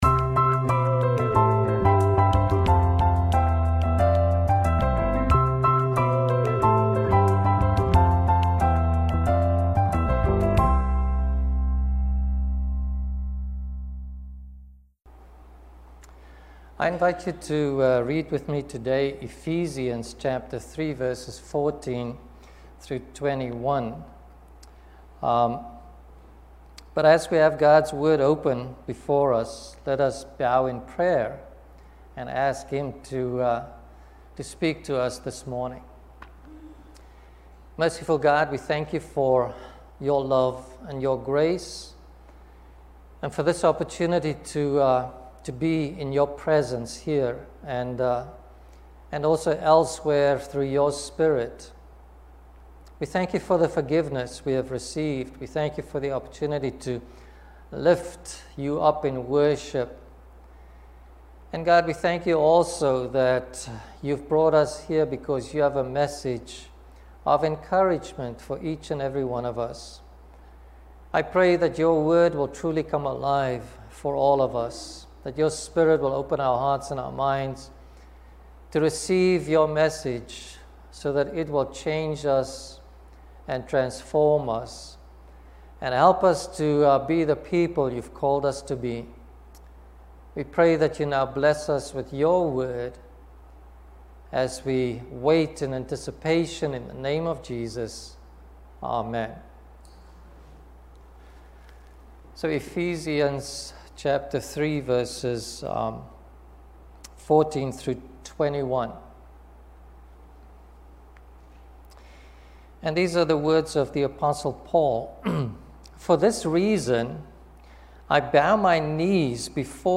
Sermons | Community Church of Douglaston